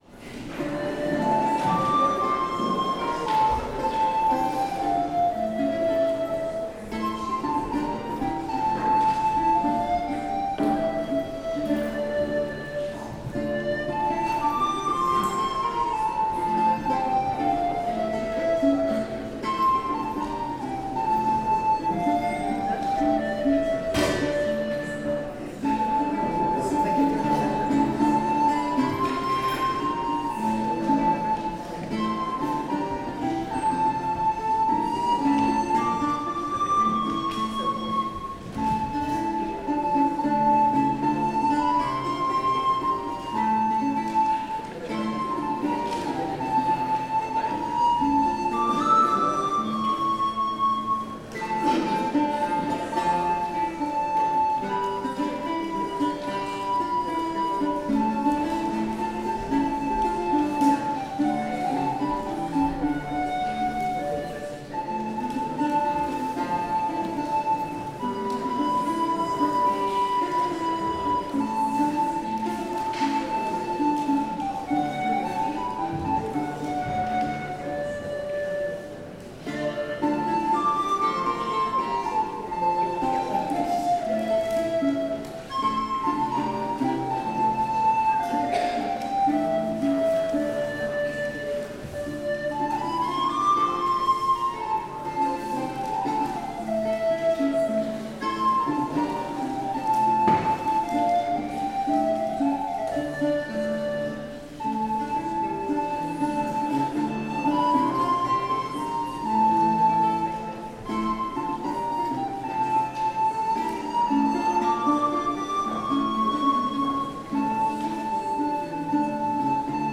Sermon and Music from Sunday